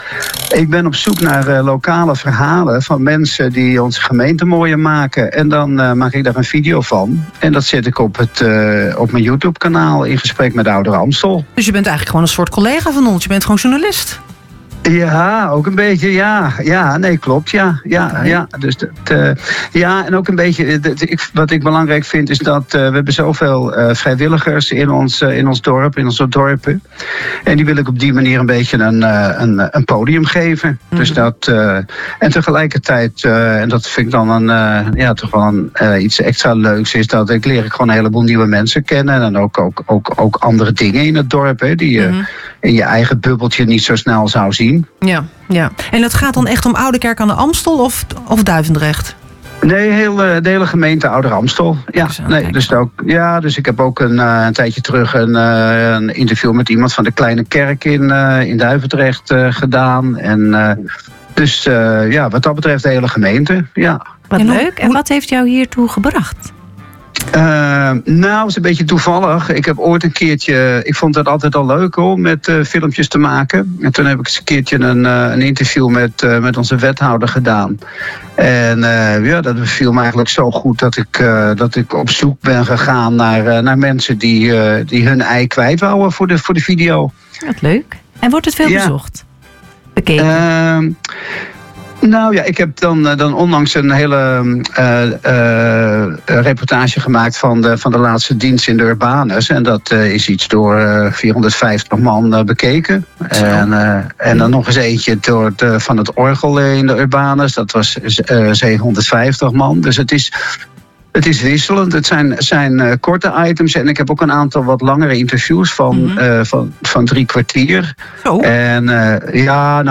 Het gesprek vond plaats in de “Streektelefoon” en gaf een mooi inkijkje in zijn motivatie en werkwijze.